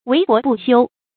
帏箔不修 wéi bó bù xiū
帏箔不修发音
成语注音ㄨㄟˊ ㄅㄛˊ ㄅㄨˋ ㄒㄧㄨ